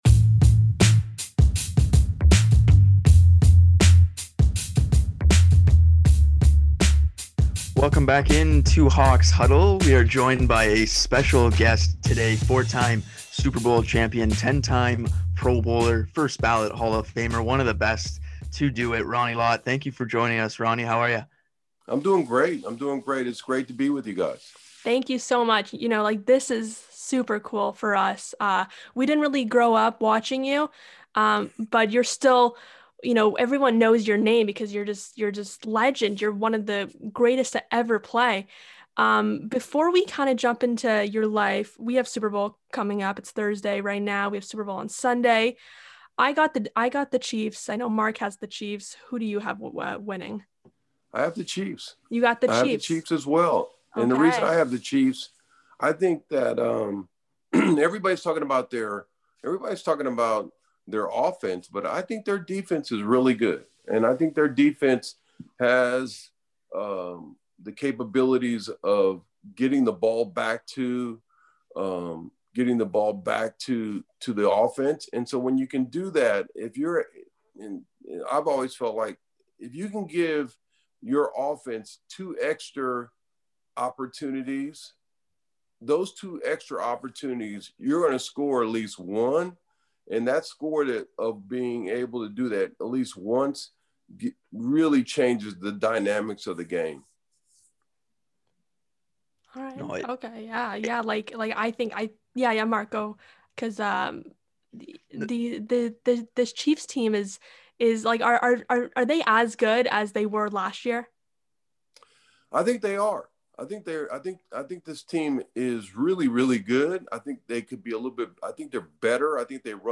Interview with NFL Legend, Ronnie Lott.